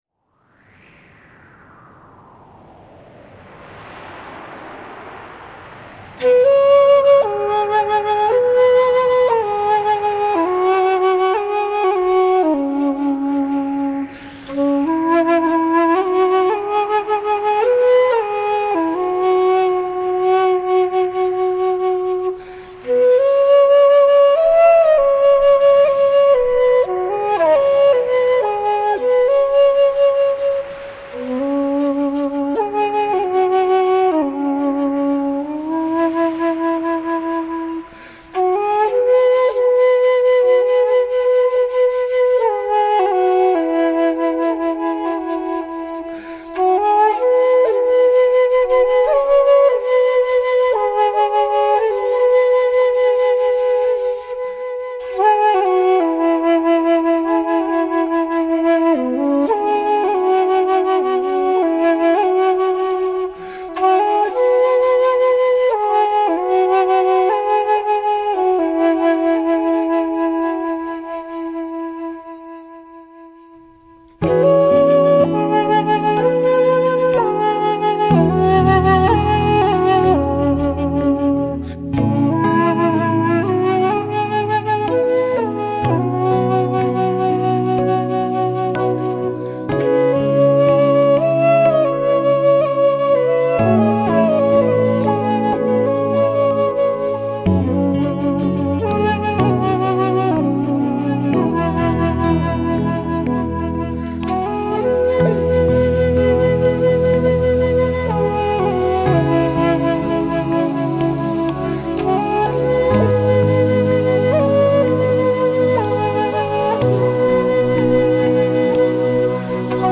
浪漫新世纪音乐